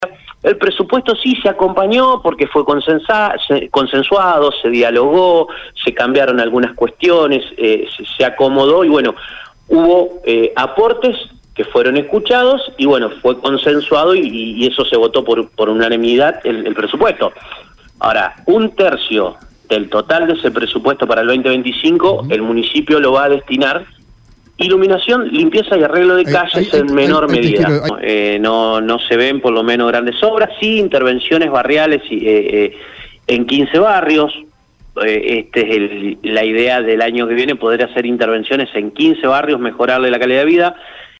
El concejal santafesino Ignacio «Nacho» Laurenti, representante de Vida y Familia, participó en una entrevista en Radio EME donde abordó varios temas de interés para la ciudad en el contexto de la reciente aprobación del Presupuesto 2025.
CONCEJAL-IGNACIO-LAURENTI-LLA-SOBRE-PRESUPUESTO-APROBADO-SF.mp3